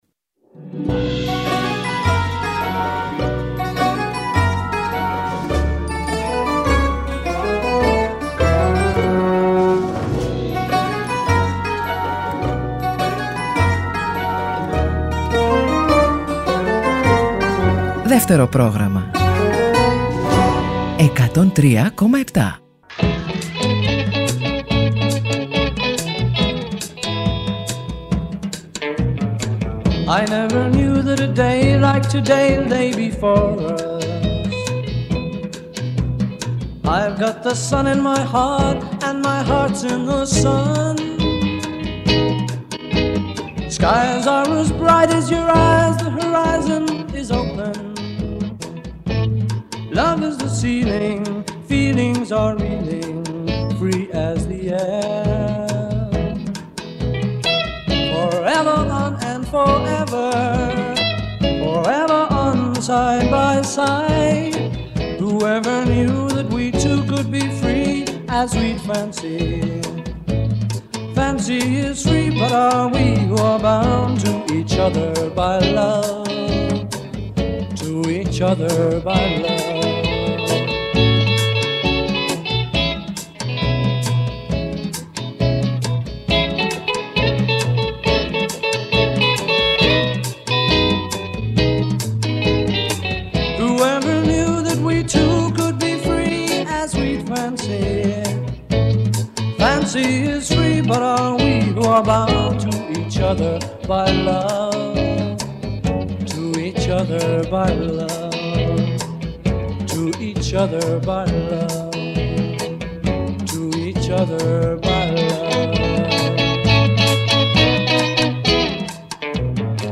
Οι δύο ερμηνεύτριες
Συνεντεύξεις